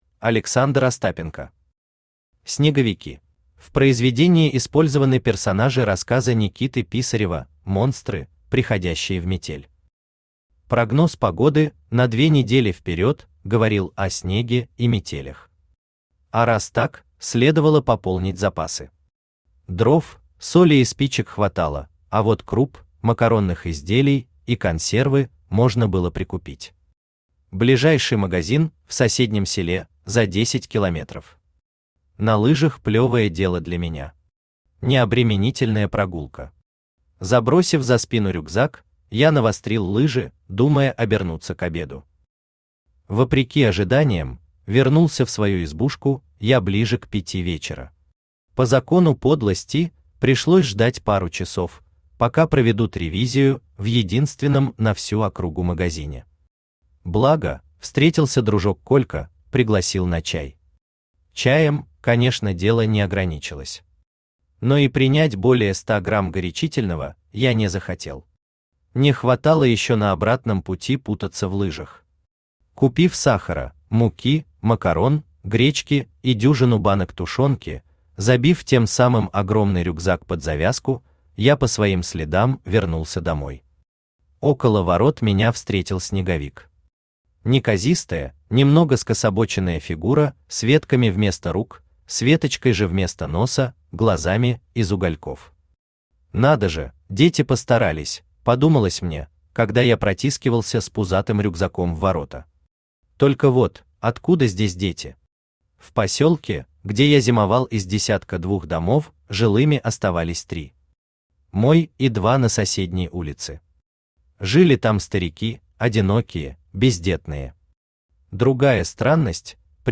Аудиокнига Снеговики | Библиотека аудиокниг
Aудиокнига Снеговики Автор Александр Викторович Остапенко Читает аудиокнигу Авточтец ЛитРес.